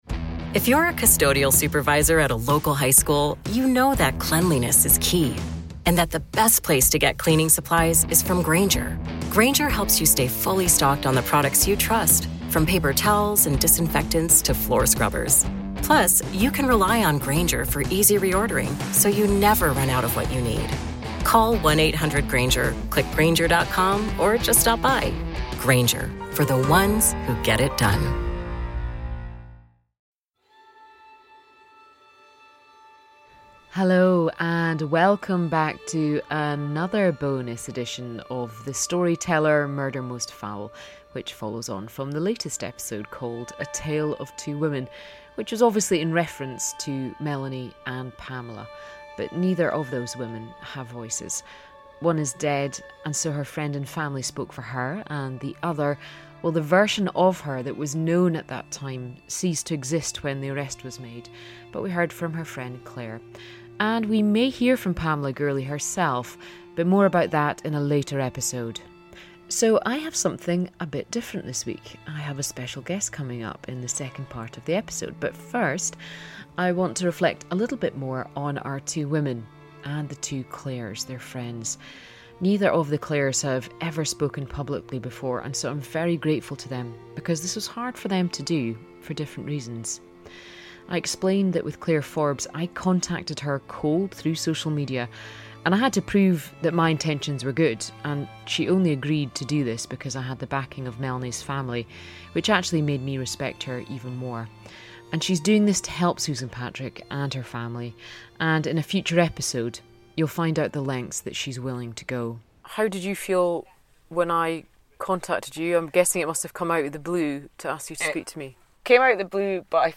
A special guest features in this bonus episode